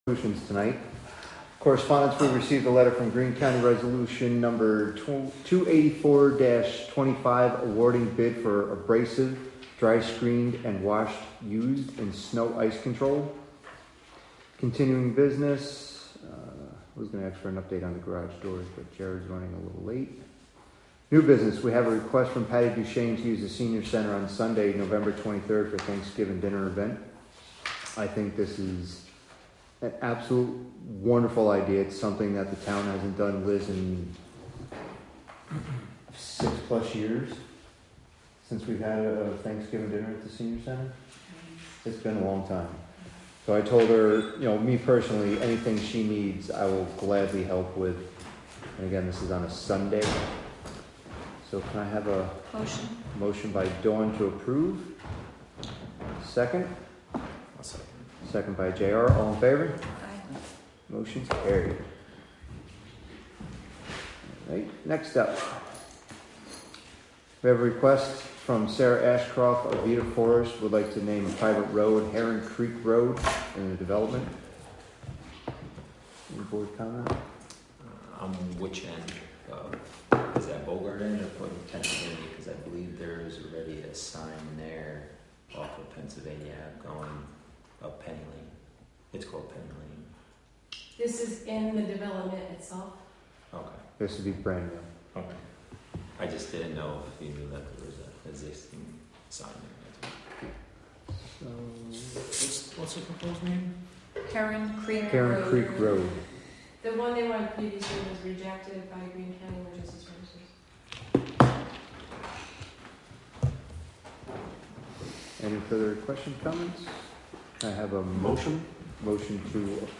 Live from the Town of Catskill: October 7, 2025 Catskill Town Board Meeting w/ Budget Workshop (Audio)